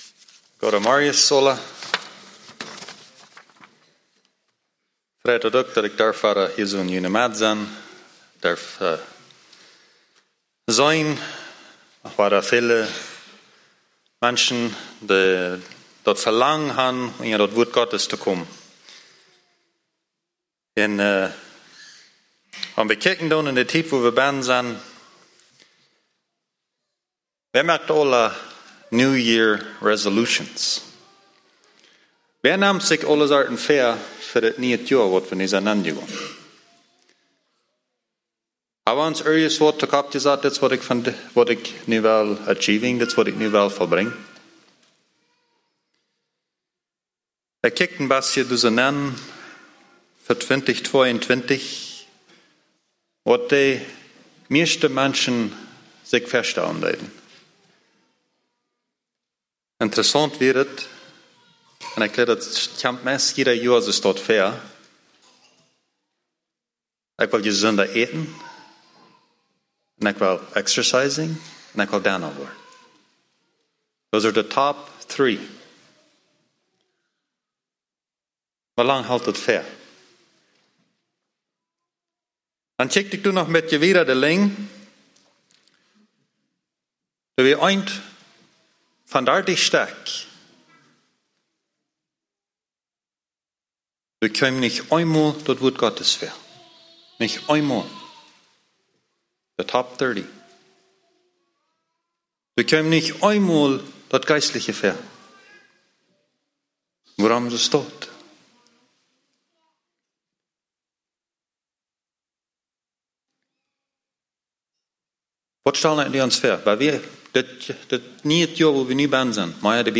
message brought on Jan. 2, 2022